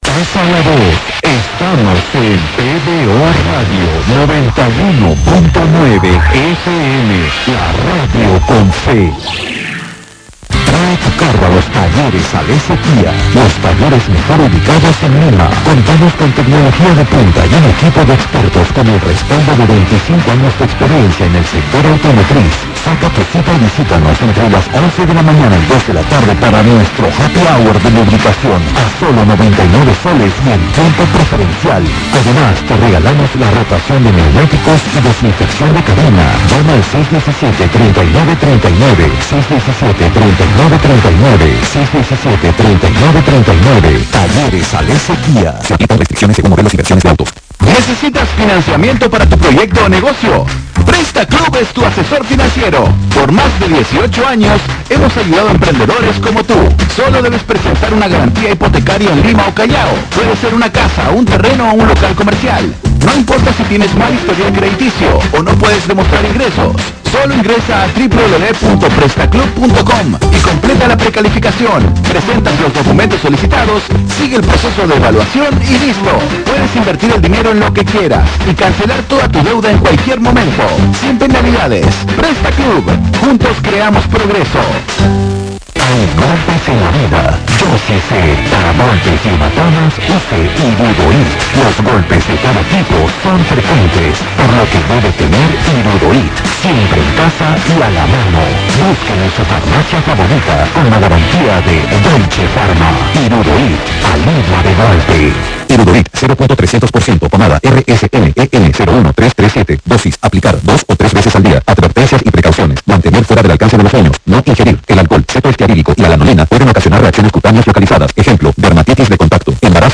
Se nota que el audio está bien saturado, y encima que lo grabé en un LG Magna LTE, en donde cualquier movimiento, la calidad del sonido se desestabiliza. Es como si escuchara una radio en AM e incluso este le gana en calidad de sonido.
Se nota que fue grabado en procesador Mediatek, ya que al saturarse el audio lo comprime.